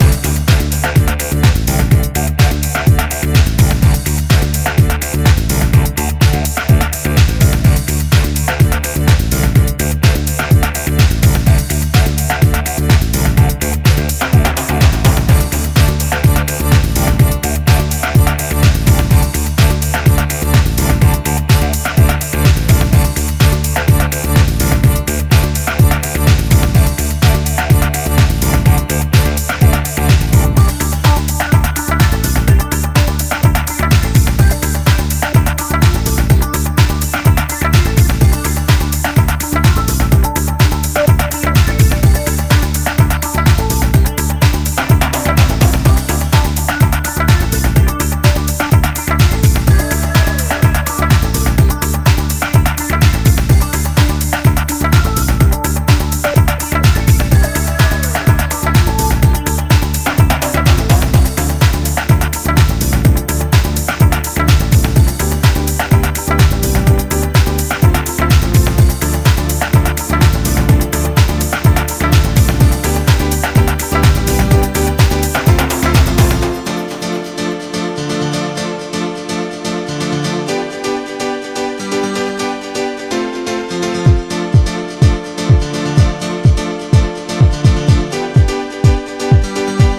後半から満を辞して登場するピアノスタブがフロアに笑顔を咲かせる